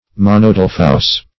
Search Result for " monodelphous" : The Collaborative International Dictionary of English v.0.48: Monodelphic \Mon`o*del"phic\, Monodelphous \Mon`o*del"phous\, a. (Zool.)
monodelphous.mp3